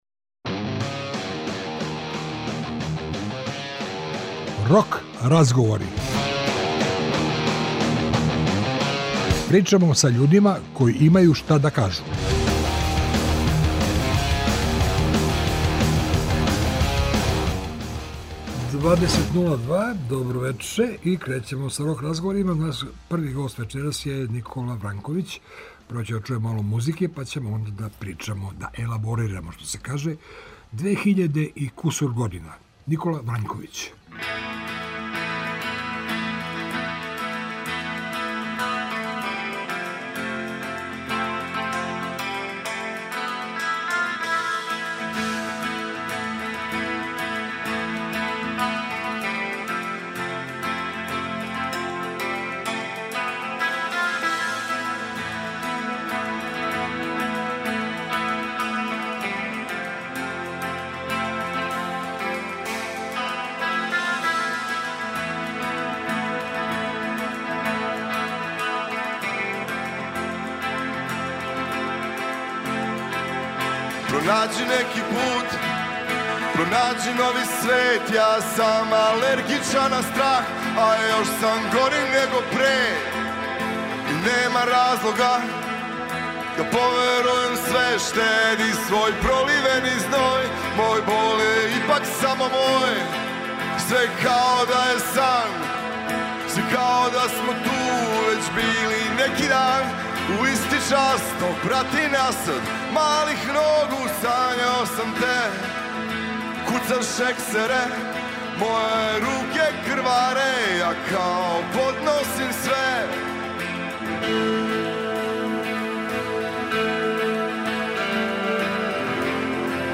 Овог четвртка нам у госте долази НИКОЛА ВРАЊКОВИЋ - поводом предновогодишњег концерта заказаног за 13. децембар у Комбанк Дворани.